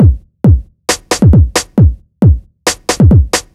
Dm Beat 135.wav